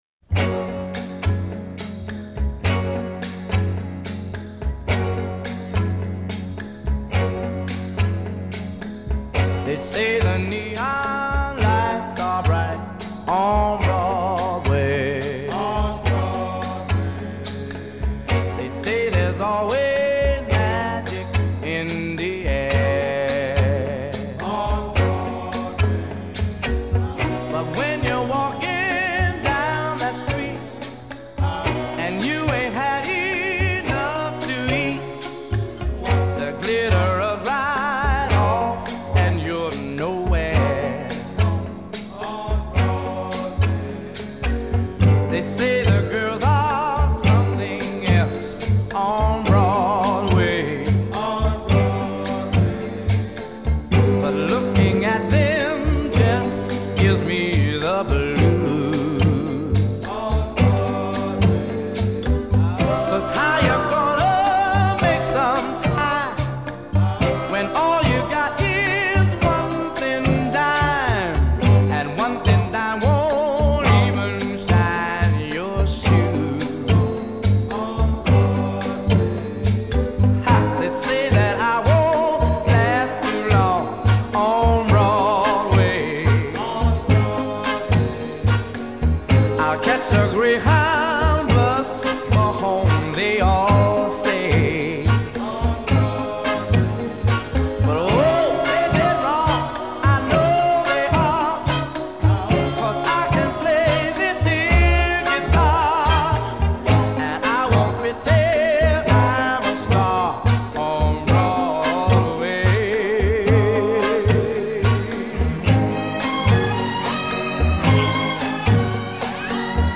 Nochmal so ein wehmütiges lied wie das über